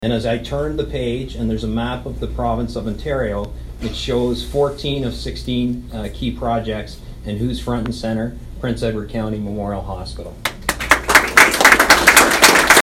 A celebration of PECMH’s centennial anniversary was held at the hospital Wednesday.